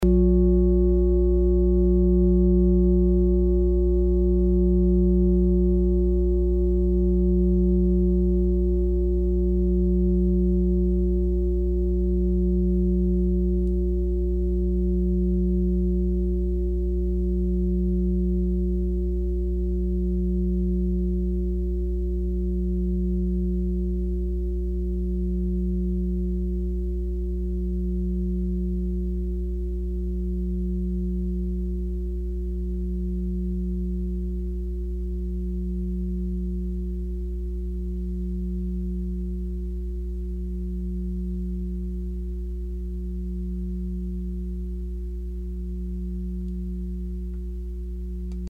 Fuß-Klangschale Nr.4
Sie ist neu und wurde gezielt nach altem 7-Metalle-Rezept von Hand gezogen und gehämmert.
(Ermittelt mit dem Gummischlegel)
fuss-klangschale-4.mp3